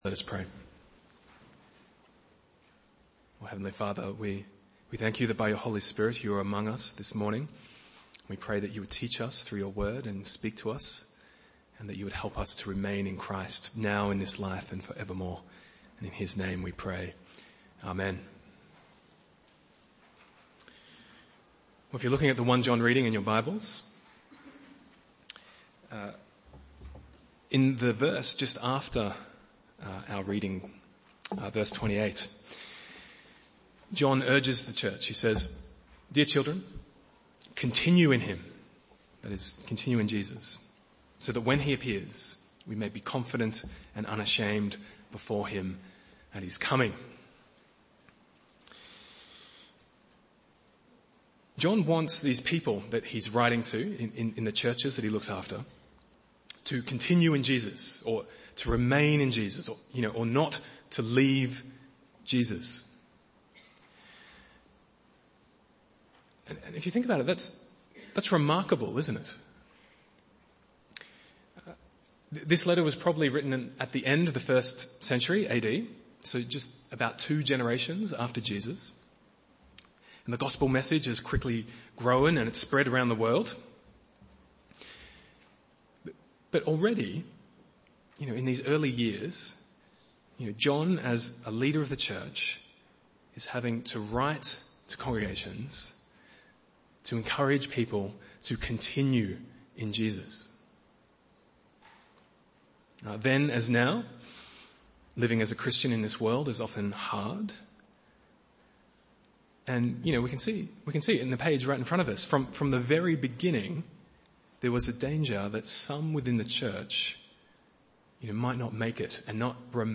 Bible Text: 1 John 2:15-27 | Preacher